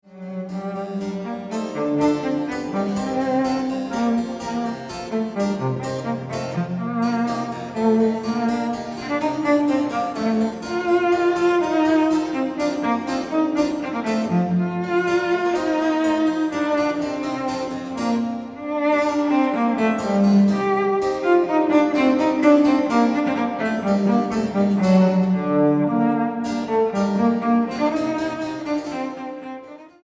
Sonata V en sol menor